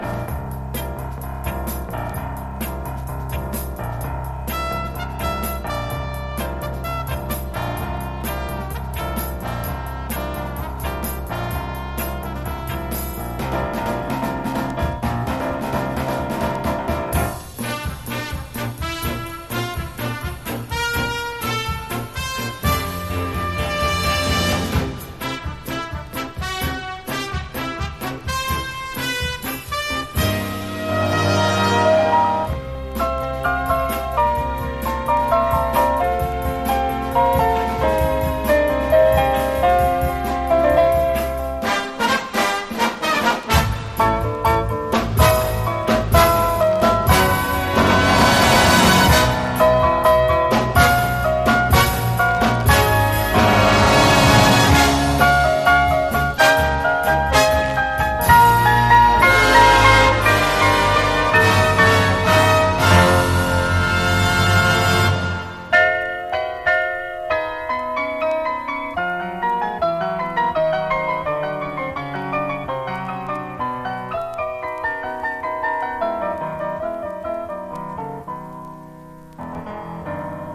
華やかな美麗オーケストラル・ピアノ・ラウンジ大傑作！
ジャズ、クラシック、ロック、カントリー、ブルースなどを横断し